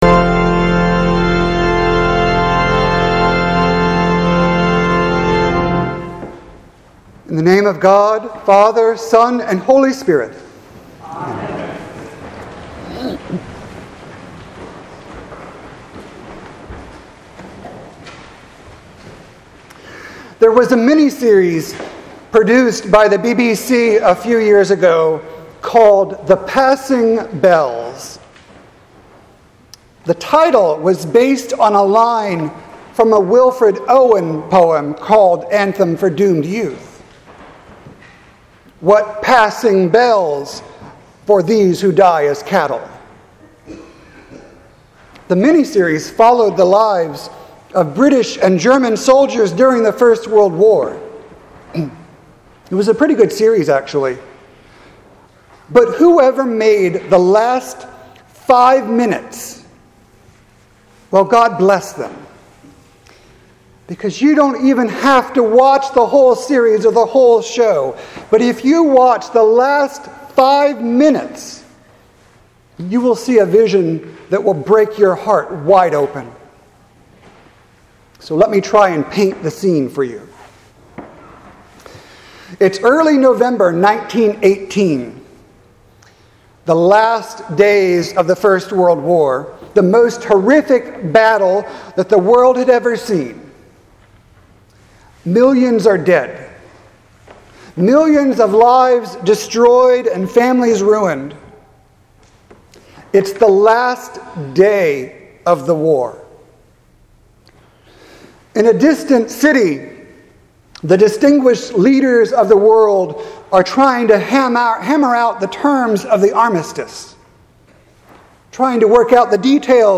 Sermon for Sunday, November 10th, 2019.